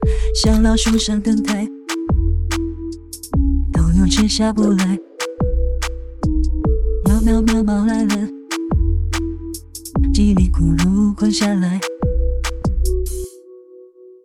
这个功能实现也是使用了工作流，流程和想法基本和上面的一致，但是主要是使用了一个AI音乐生成插件来实现出音乐的创造。